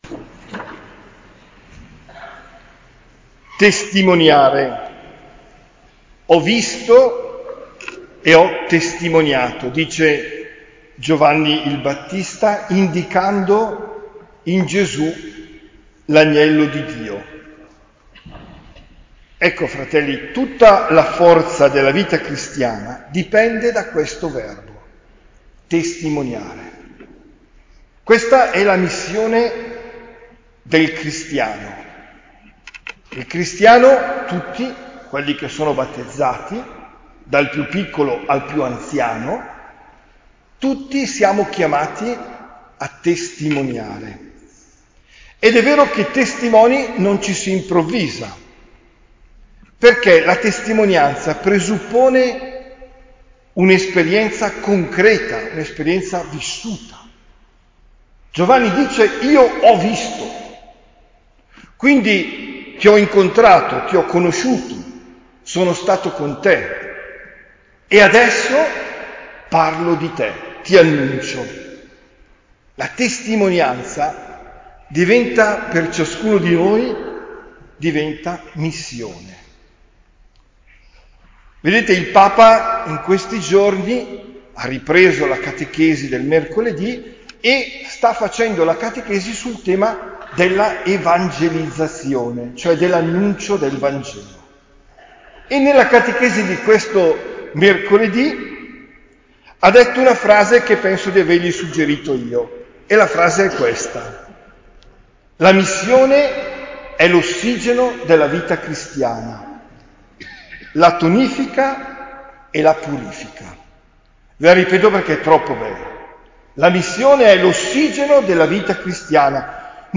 OMELIA DEL 15 GENNAIO 2023